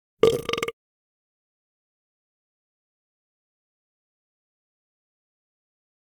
petburp.ogg